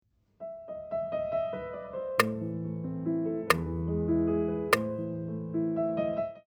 If you listen to Fur Elise, it is clear where the beat is, right?
Yes, where the ugly clicks are.